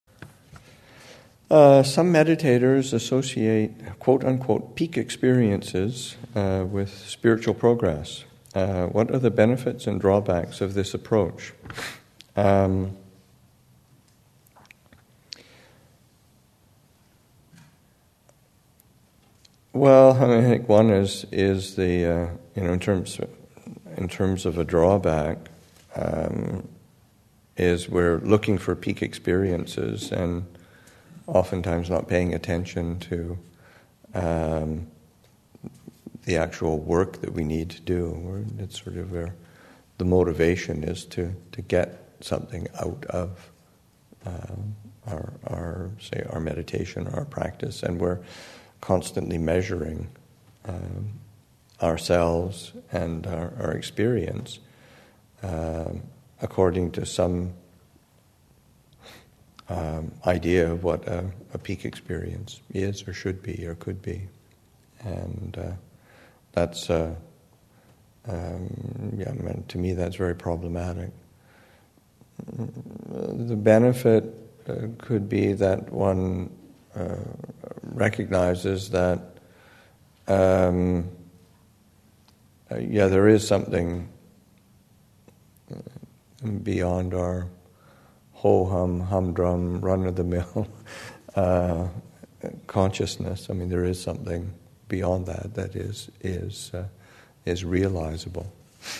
2015 Thanksgiving Monastic Retreat, Session 4 – Nov. 24, 2015